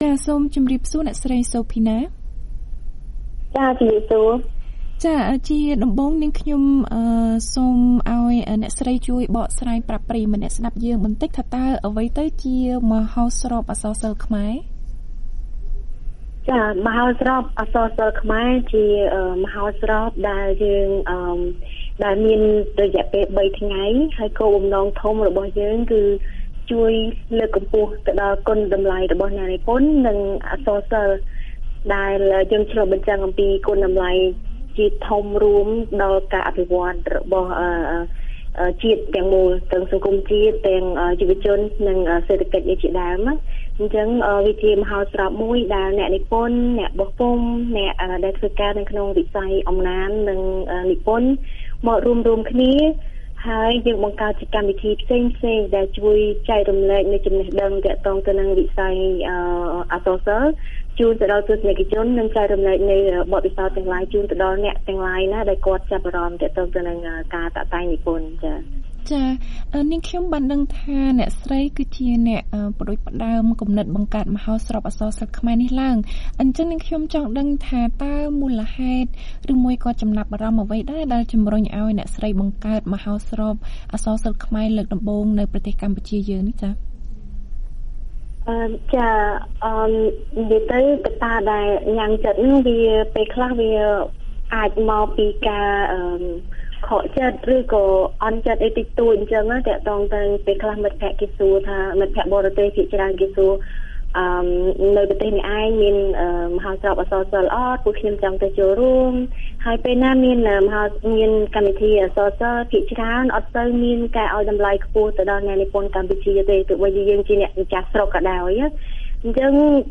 បទសម្ភាសន៍ VOA៖ មហោស្រពអក្សរសិល្ប៍ខ្មែរលើកទី៣ជំរុញឲ្យសាធារណជនស្គាល់និងឲ្យតម្លៃលើស្នាដៃនិពន្ធថ្មីៗ